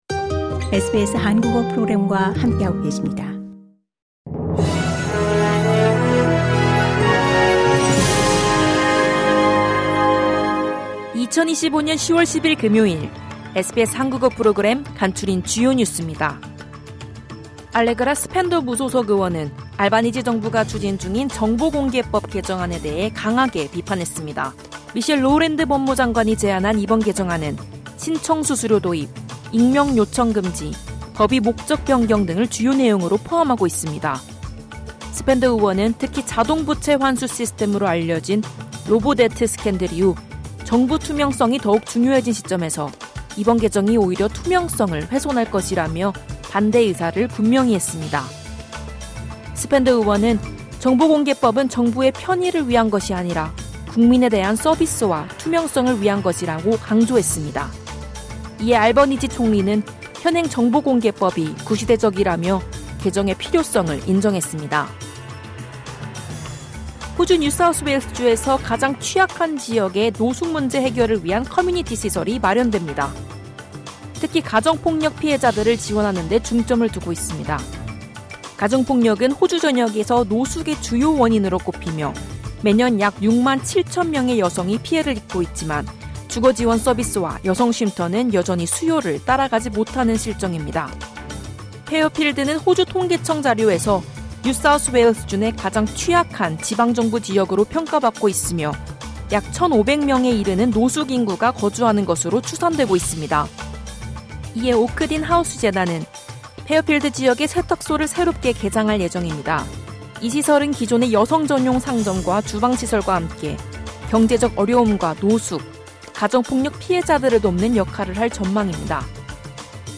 호주 뉴스 3분 브리핑: 2025년 10월 10일 금요일